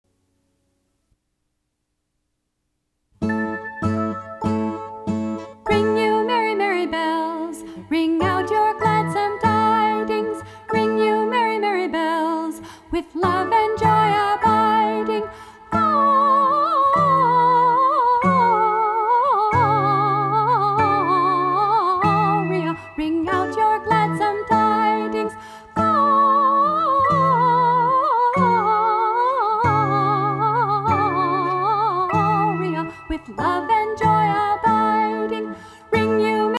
Traditional Lyrics
Sing as a round: